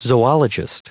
Prononciation du mot zoologist en anglais (fichier audio)
Prononciation du mot : zoologist